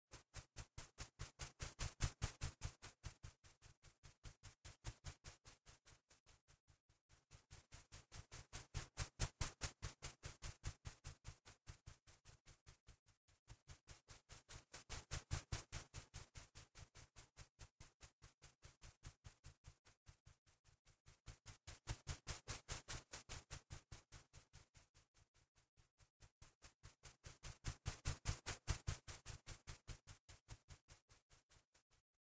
Звук полета птицы в тихом месте